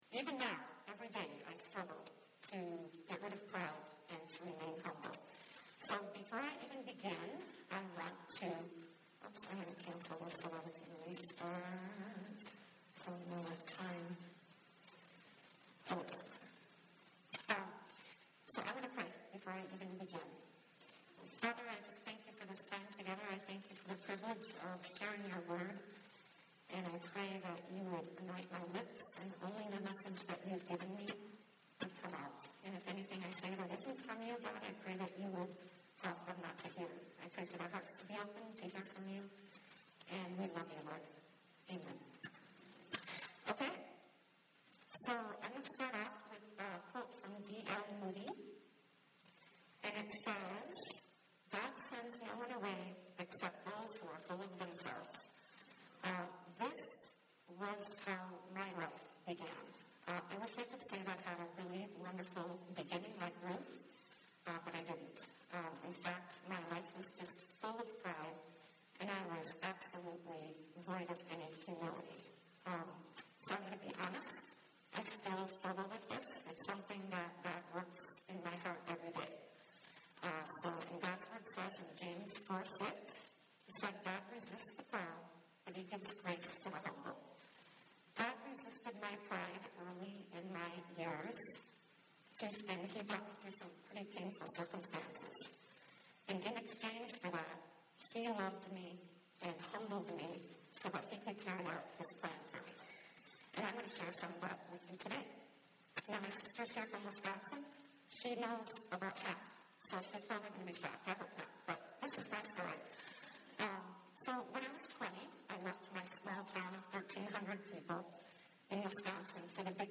Playlists Women's Retreat 2019: Ruth A True Story Women's Ministry Retreats and Conferences undefined Ruth Topics Humility Download Audio